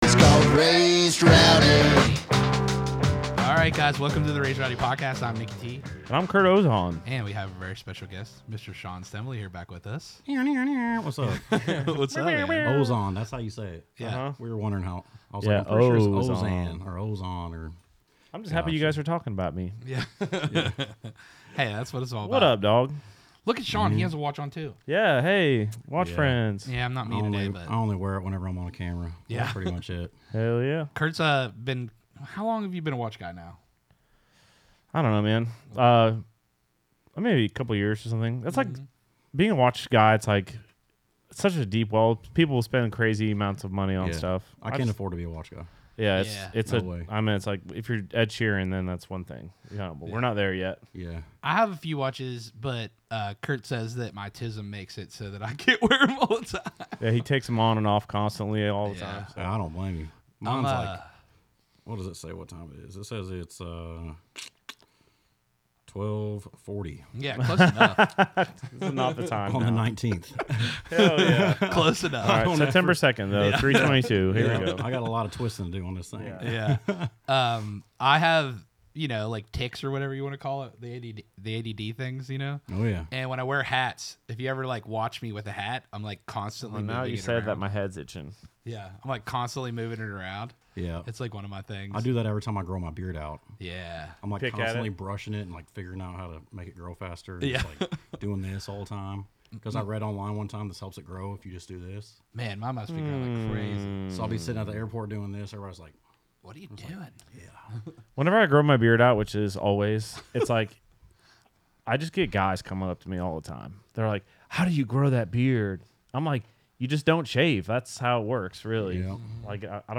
for a high-energy and wide-ranging conversation